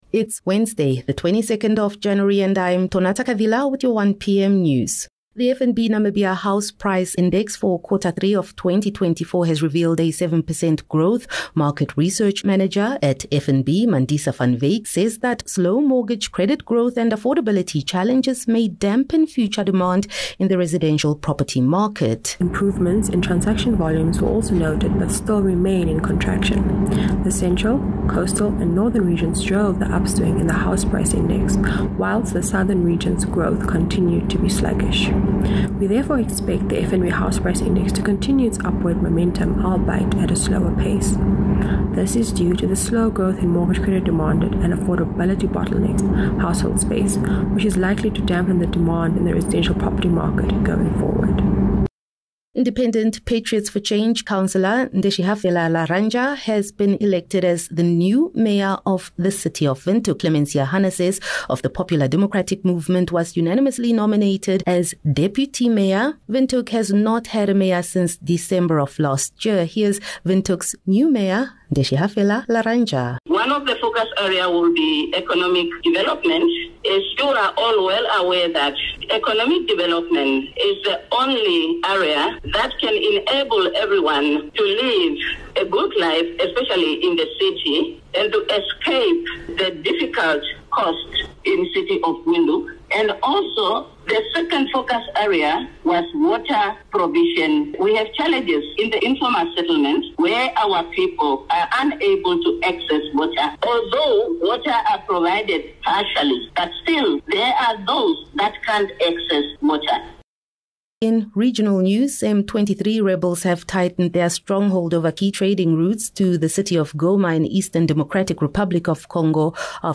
Daily bulletins from Namibia's award winning news team.